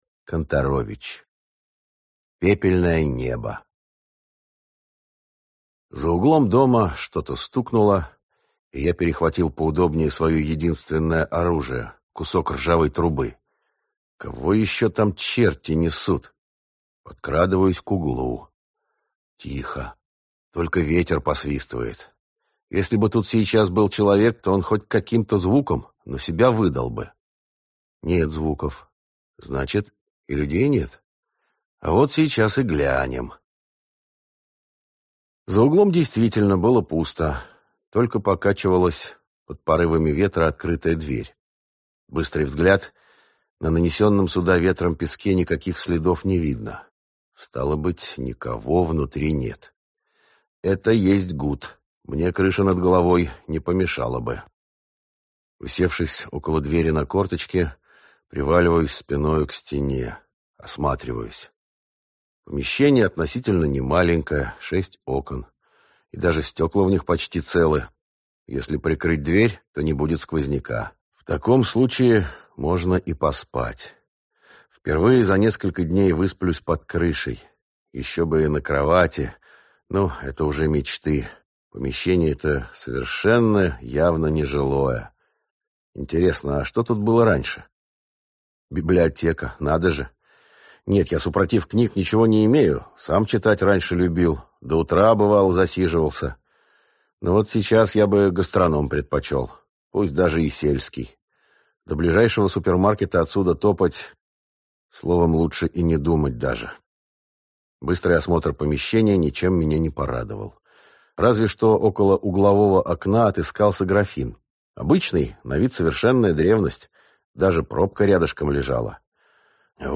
Аудиокнига Пепельное небо | Библиотека аудиокниг